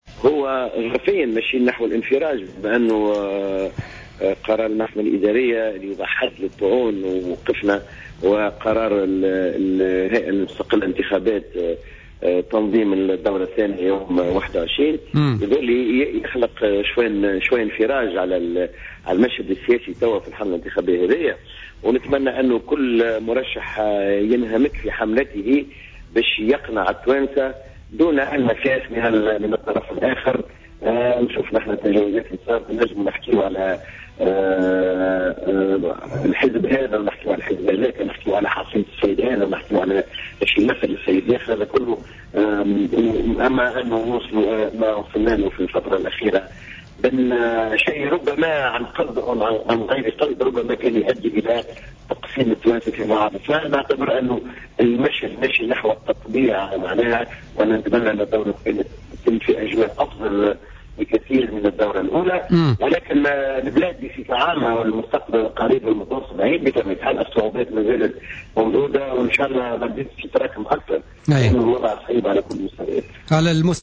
قال سمير بالطيب أمين عام حزب المسار الديمقراطي الاجتماعي، ضيف بوليتيكا اليوم الاثنين إن قرار الهيئة العليا المستقلة للانتخابات بتنظيم الدور الثاني من الانتخابات الرئاسية يوم 21 ديسمبر يخلق شيئا من الانفراج في المشهد السياسي شريطة أن ينهمك كل مرشح في استعراض برنامجه الانتخابي دون المساس من الطرف الآخر.